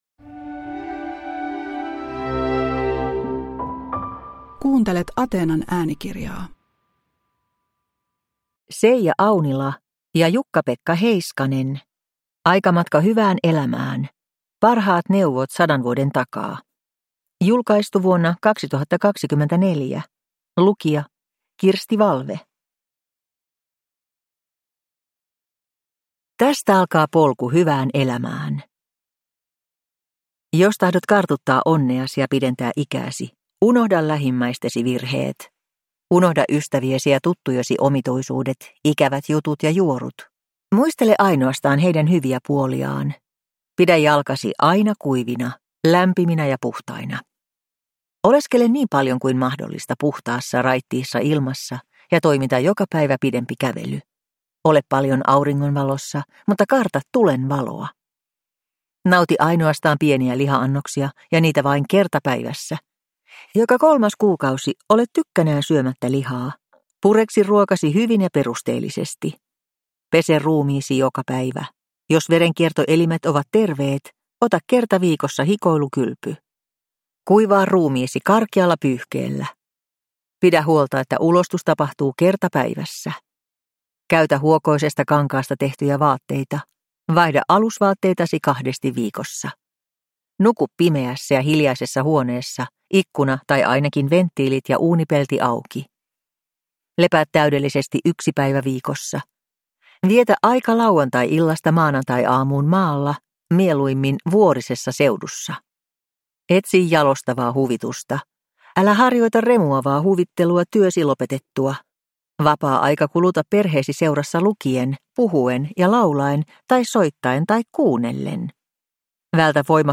Aikamatka hyvään elämään – Ljudbok